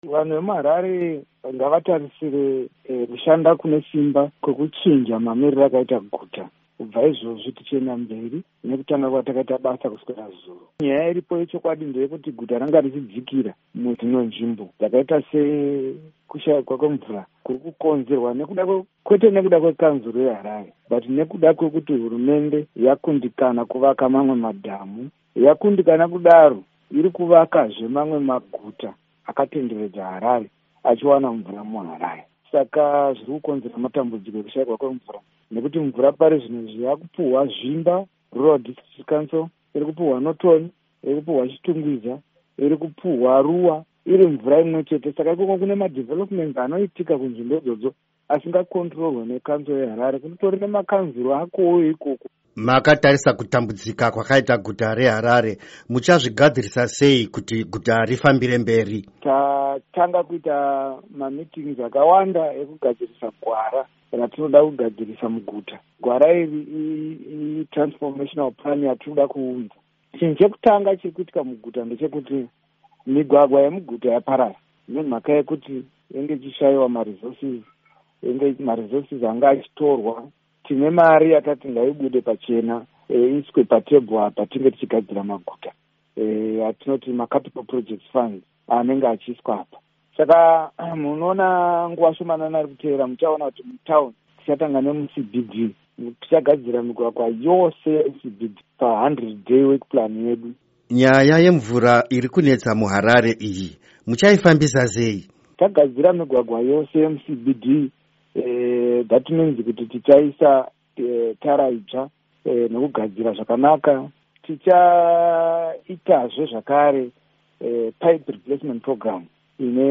Hurukuro naVaHerbert Gomba